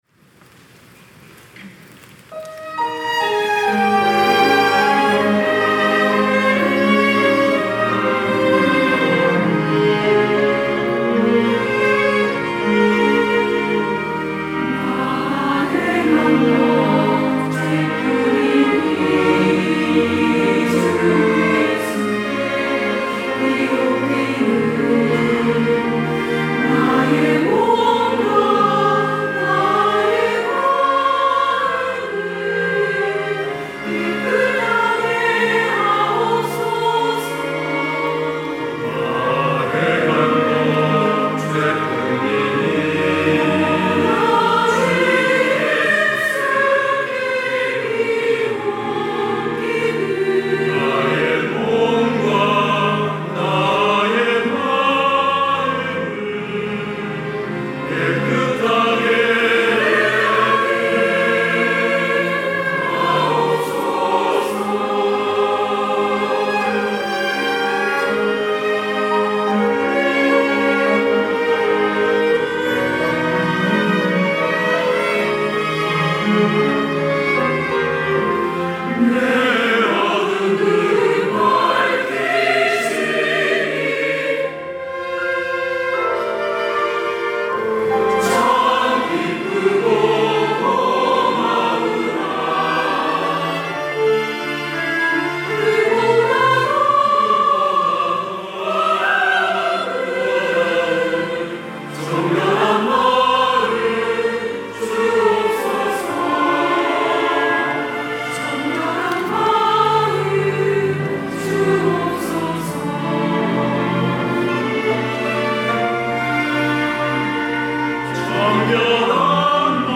호산나(주일3부) - 나 행한 것 죄뿐이니
찬양대